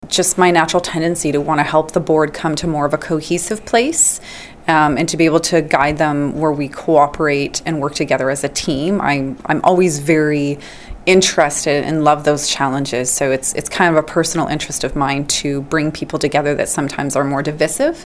We asked Watson why she was interested in the job.